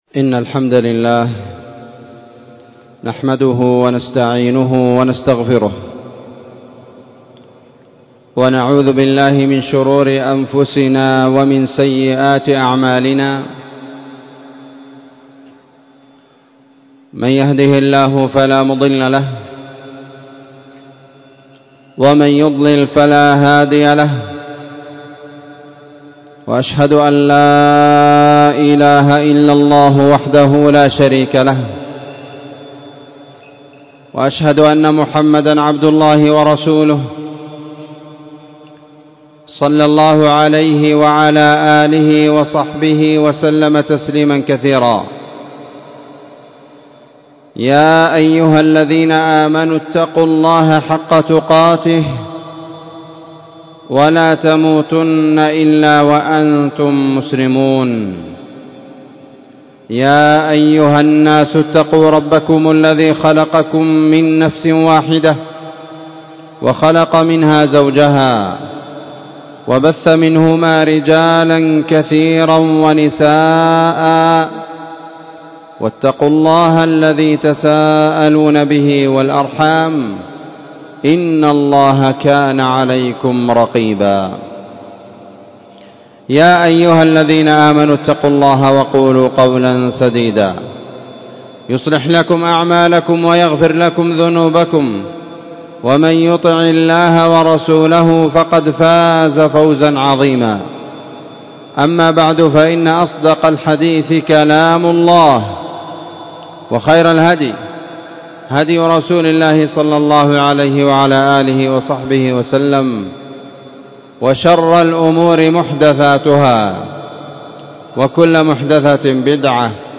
إلى آخر الحديث ٢٠ ذي القعدة ١٤٤٤ تاريخ النشر 2023-06-09 وصف خطبة جمعة بعنوان: شرح حديث:(( إياكم والظن فإن الظن أكذب الحديث...))